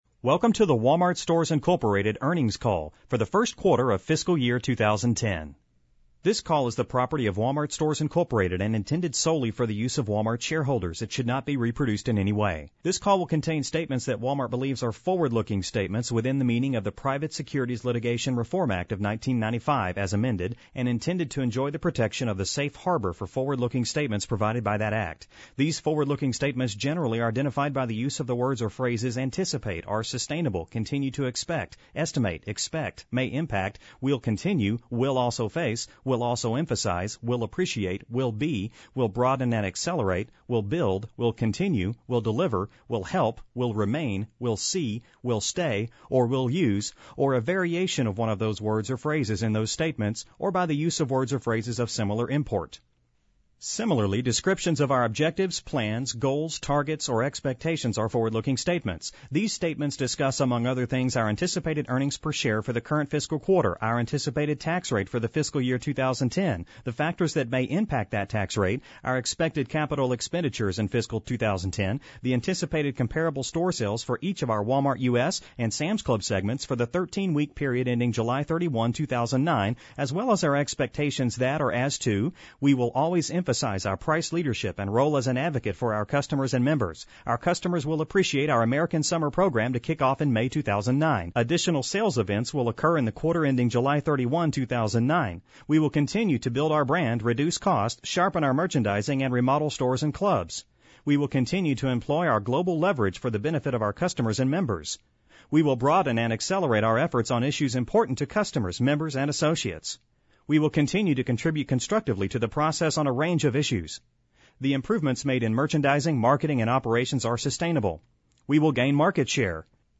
Walmart Report: FY2010 Q1 Earnings Call Pre-Recorded Phone Call